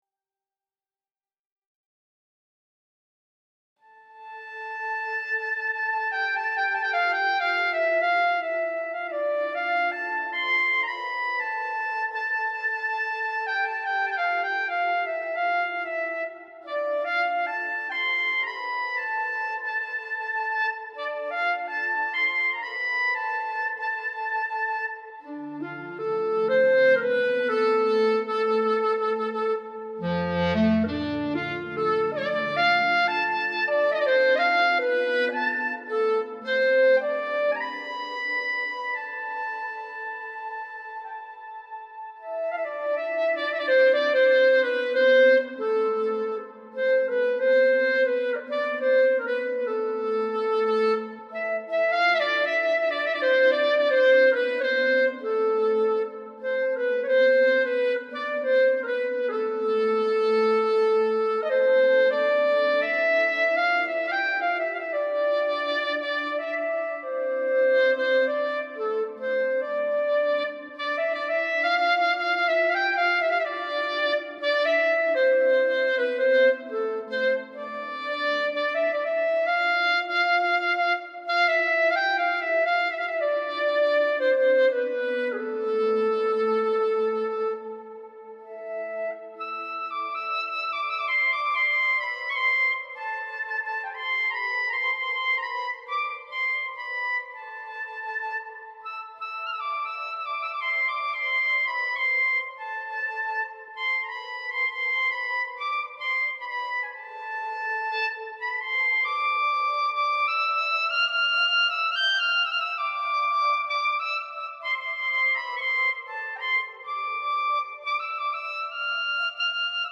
Adding pads from Omnisphere creates nice backgrounds, and there are hundreds of pads and other voices to explore.
A few samples of my brain's creation (highly flawed, no corrections, taken as it comes) are below.
26-Scheherezade 2+3, Swam TS+Cl+Omni LeMusician Bleu+Breathing Glass Fl.m4a